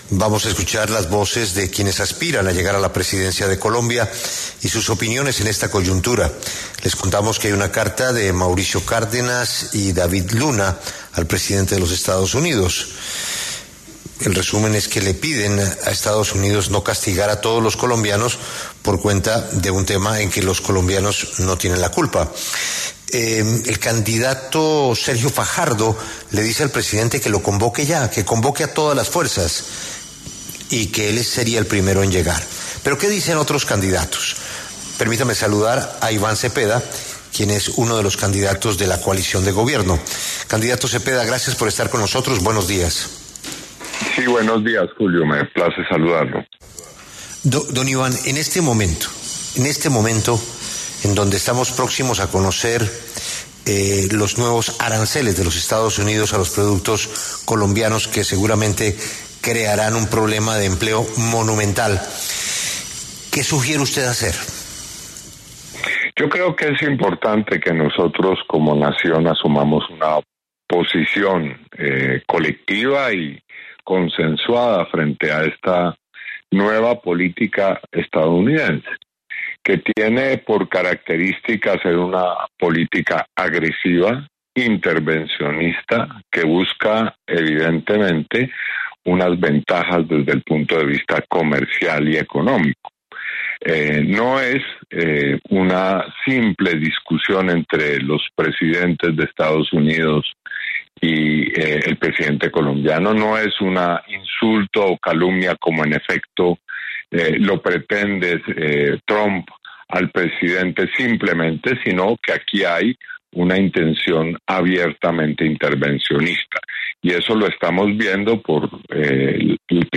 Los precandidatos presidenciales Iván Cepeda, Abelardo de la Espriella y Juan Manuel Galán pasaron por los micrófonos de La W, con Julio Sánchez Cristo, para hablar sobre la nueva tensión entre los presidentes Donald Trump, de Estados Unidos, y Gustavo Petro, de Colombia.